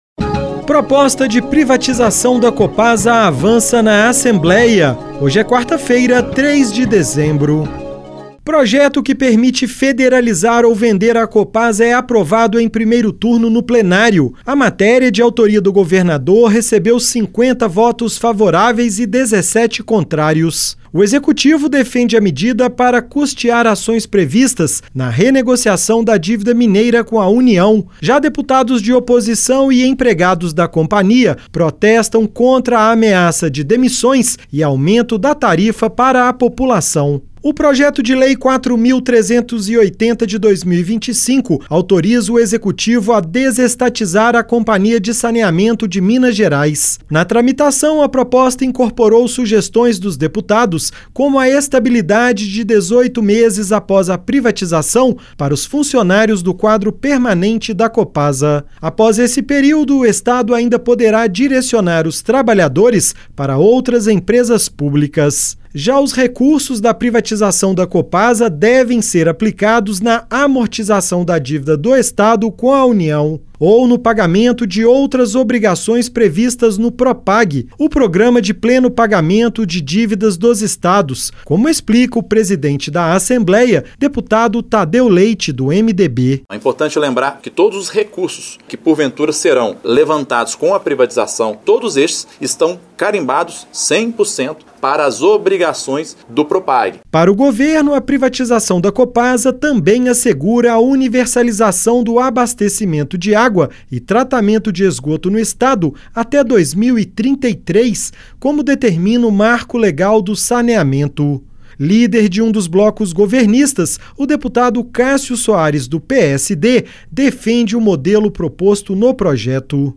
Boletim da ALMG - Edição n.º 6309